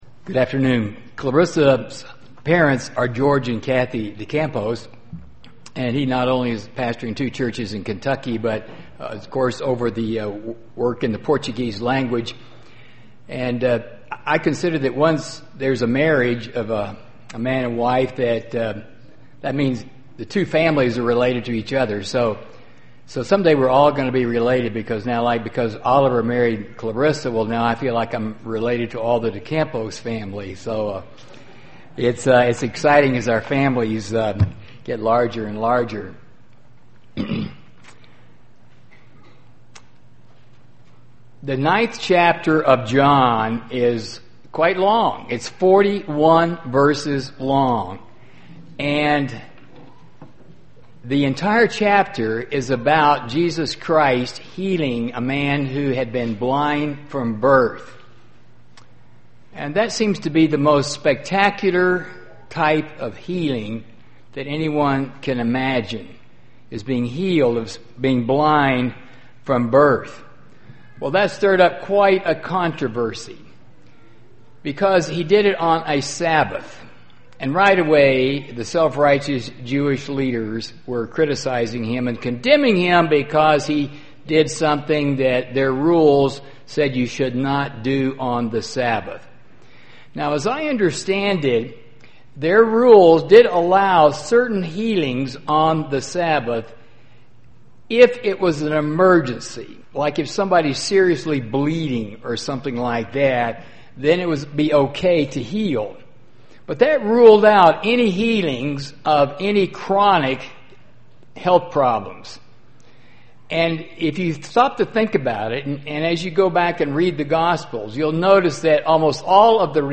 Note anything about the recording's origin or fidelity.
This sermon was given at the Canmore, Alberta 2013 Feast site.